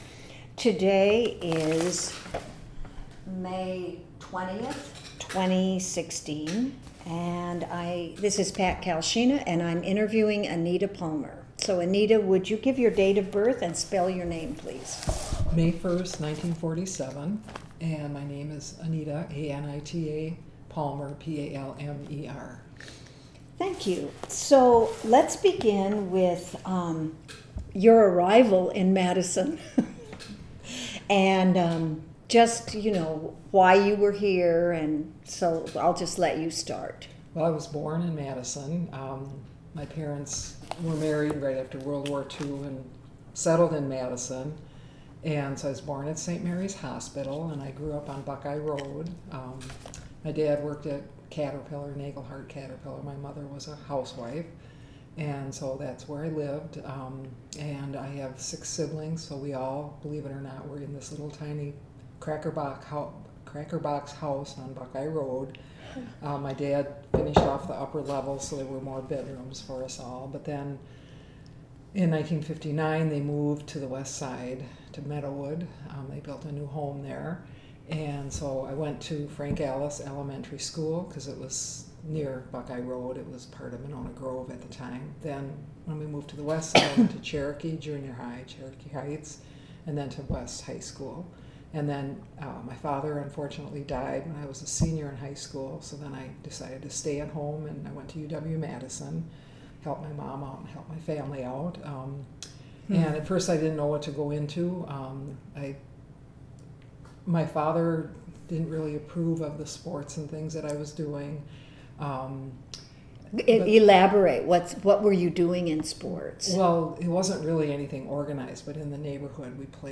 Oral History Interview
The following topics were discussed: women’s sports, especially basketball, coaching, lesbianism, Title 1X, East High School. This interview was conducted for the UW-Madison Oral History Program.